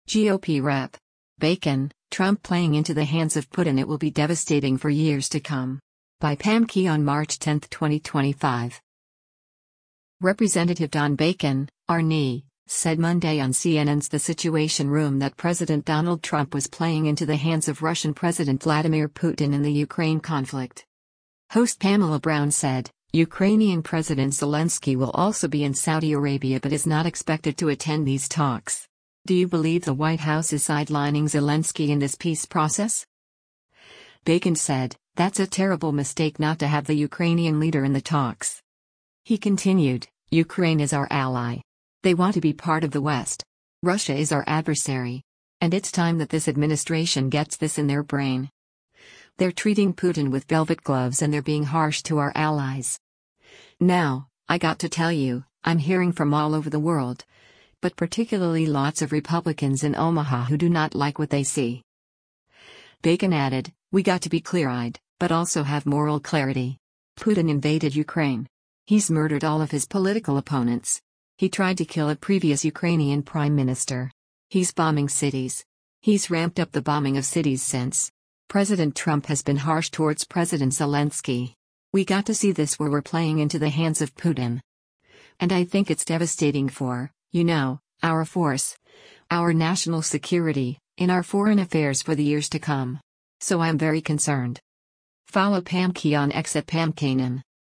Representative Don Bacon (R-NE) said Monday on CNN’s “The Situation Room” that President Donald Trump was playing into the hands of Russian President Vladimir Putin in the Ukraine conflict.
Host Pamela Brown said, “Ukrainian President Zelensky will also be in Saudi Arabia but is not expected to attend these talks.